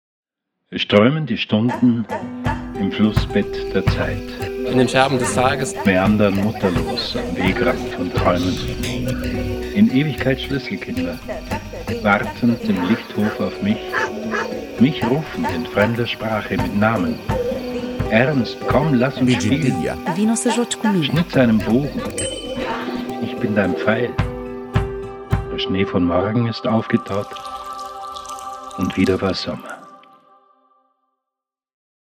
Wintergedicht, Lyrisches, Audiocollage, Indianersommer